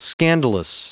Prononciation du mot scandalous en anglais (fichier audio)
Prononciation du mot : scandalous